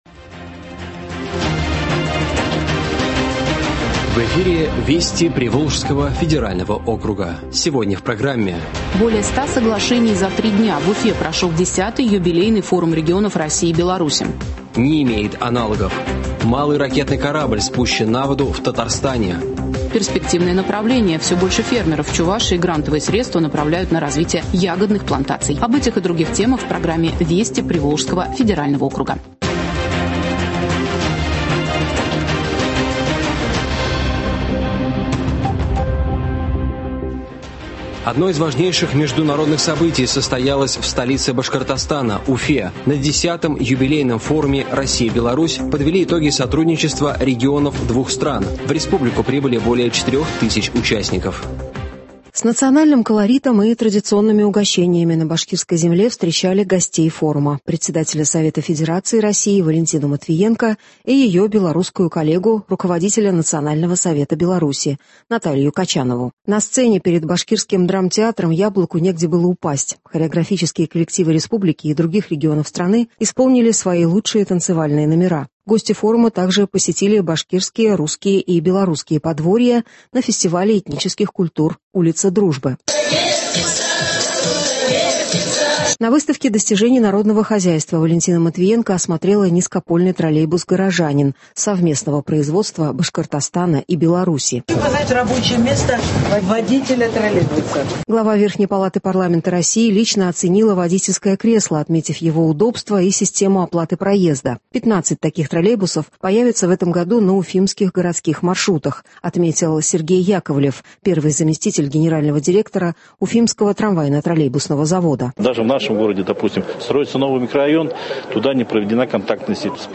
Радио обзор событий недели в регионах ПФО.